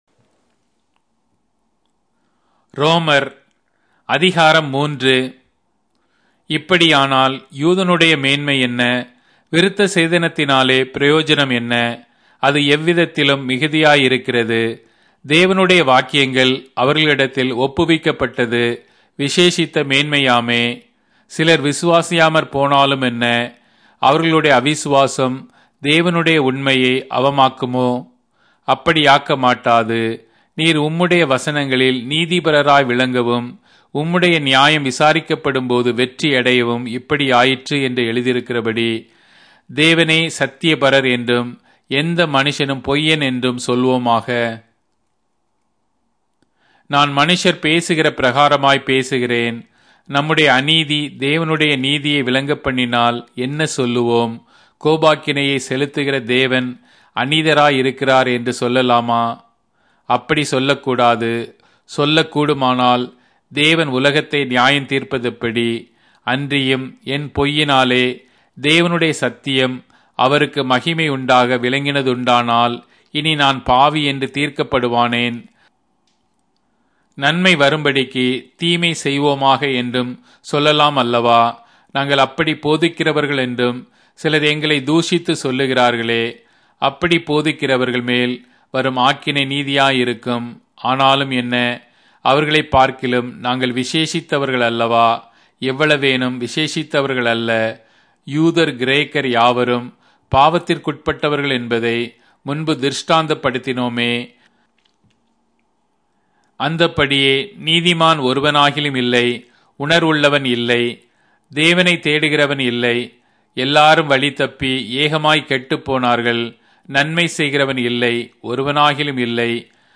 Tamil Audio Bible - Romans 7 in Orv bible version